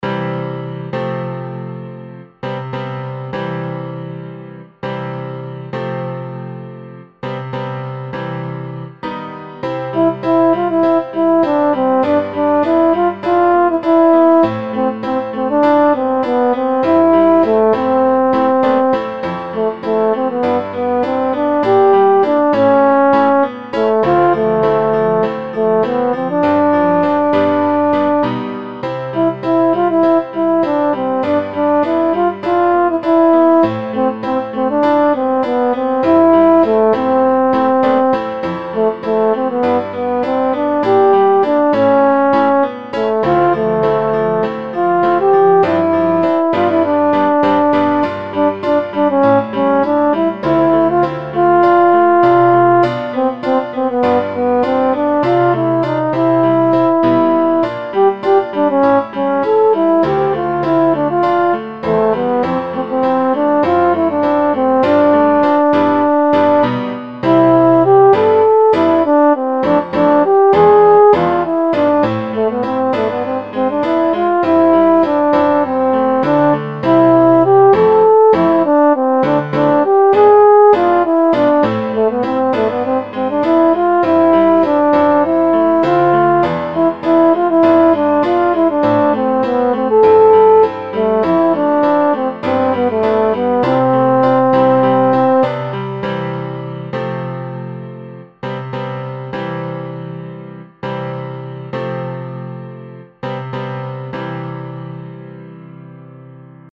ぼくらの旅立ち（ホルン＆ピアノ）
07_bokura_no_tabidachi_horn&piano.mp3